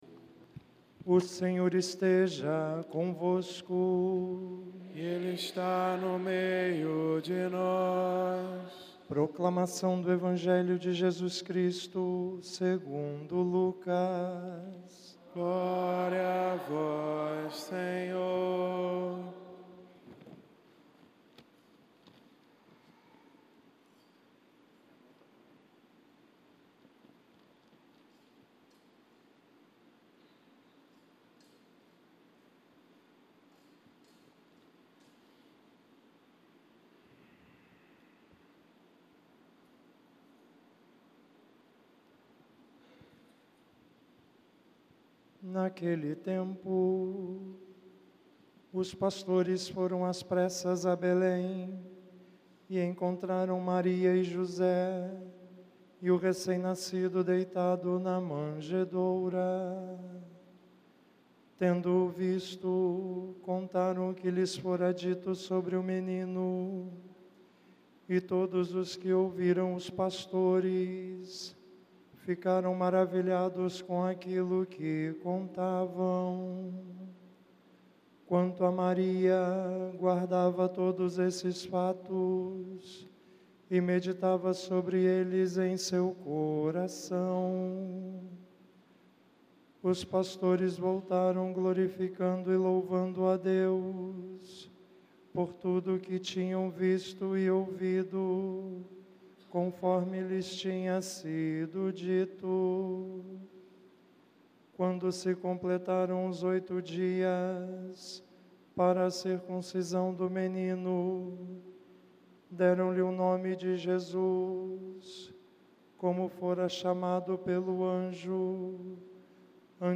Homilia 01/01/2020 – Solenidade de Santa Maria, Mãe de Deus – Quarta-feira